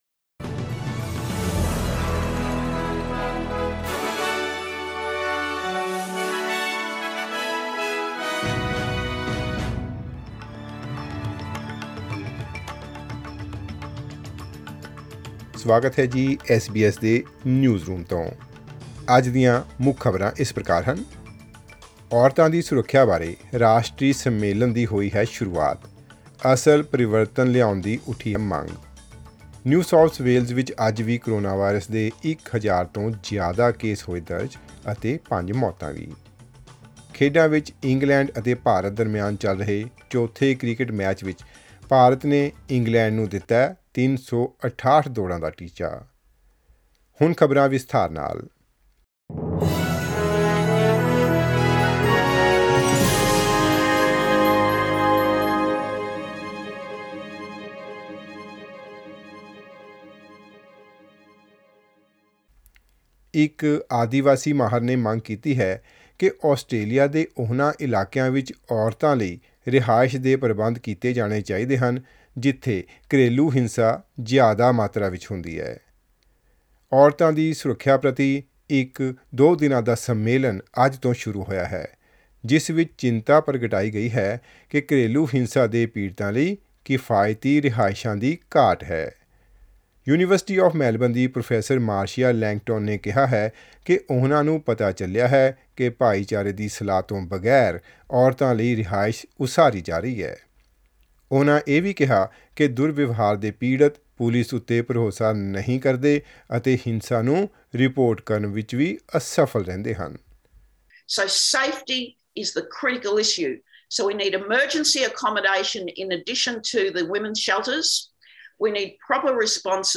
Listen to the latest news headlines in Australia from SBS Punjabi radio.